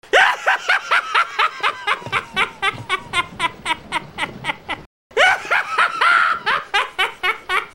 Chucky Laugh.mp3